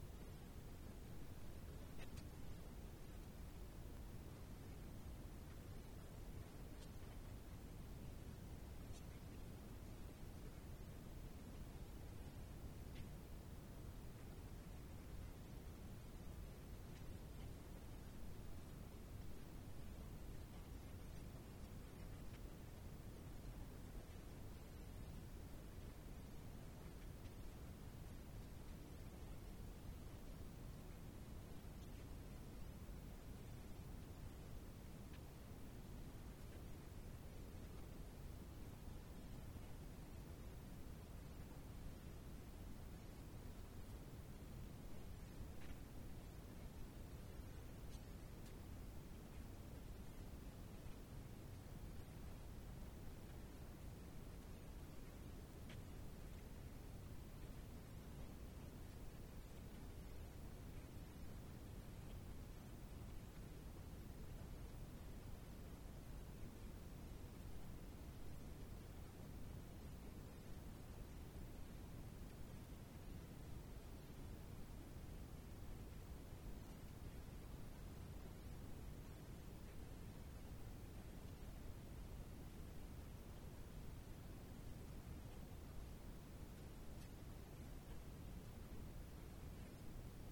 Ecco le dichiarazioni raccolte nella giornata di presentazione del Report 2023: